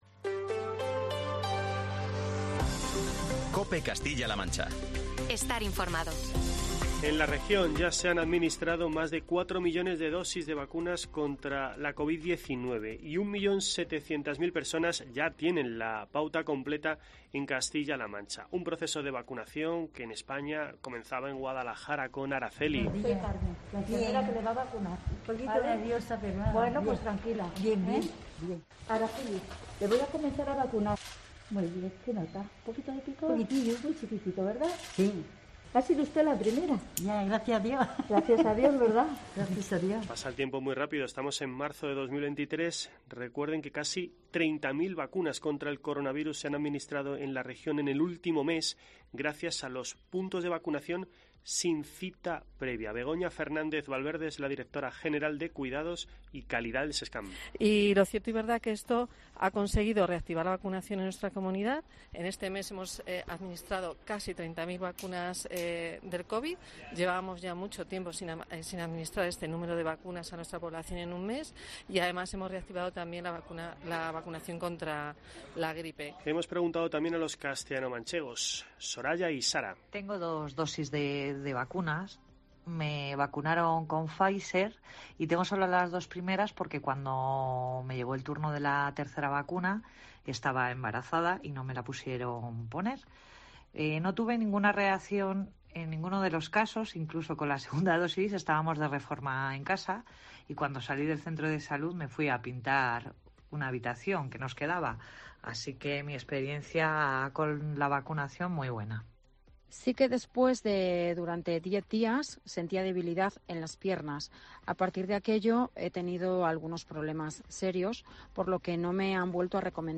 Así lo ha explicado en los micrófonos de COPE Castilla-La Mancha la directora general de Cuidados y Calidad del SESCAM (Servicio de Salud de Castilla-La Mancha), Begoña Fernández Valverde.